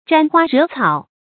沾花惹草 zhān huā rě cǎo 成语解释 犹言沾风惹草。